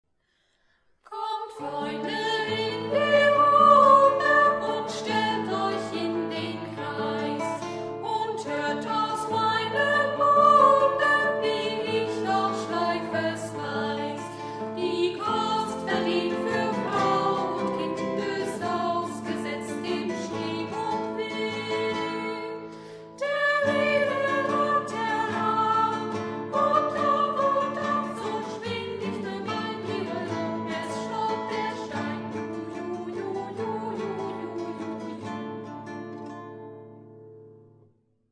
Dieses Lied wurde vom Sextett der Freien Waldorfschule Halle eingesungen.